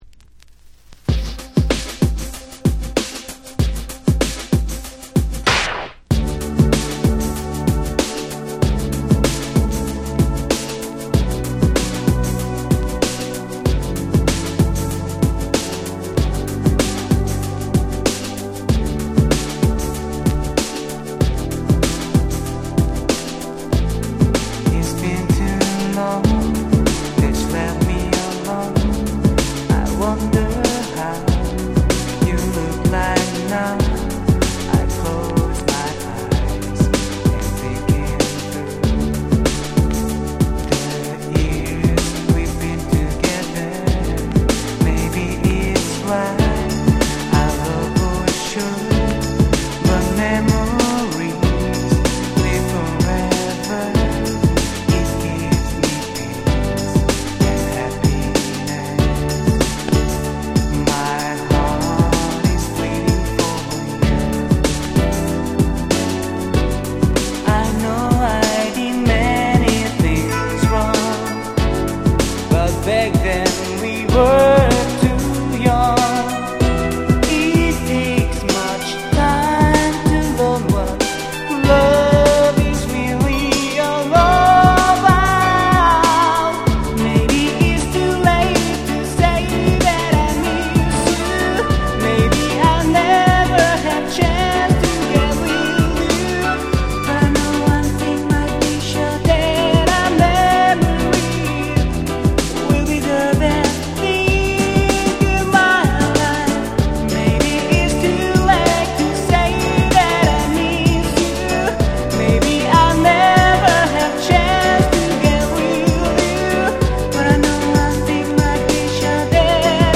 【Condition】C (全体的に薄いスリキズが多めですがDJ Play可。
※両面共に全トラック最初から最後まで試聴ファイルを録音してございます。
93' Very Nice Ground Beat !!